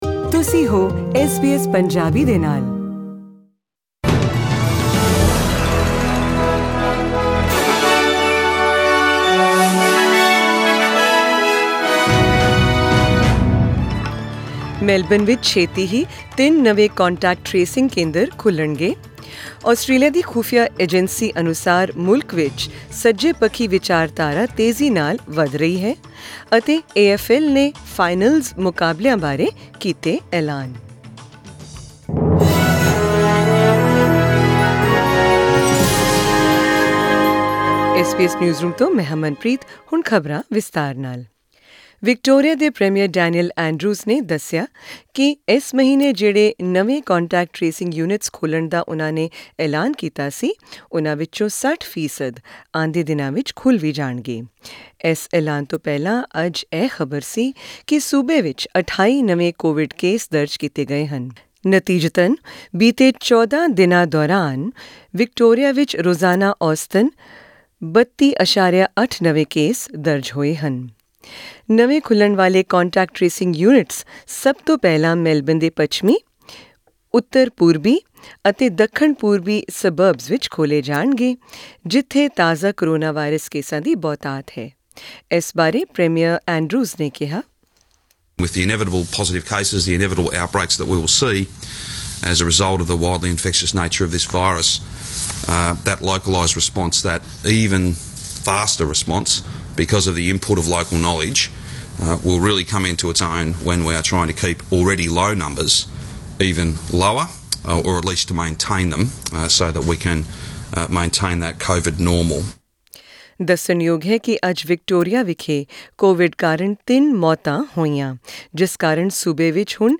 In this news bulletin, we bring you the latest on the coronavarius pandemic from around Australia and the revelation from Australia's domestic spy agency about the rapid rise of right-wing extremism in the nation.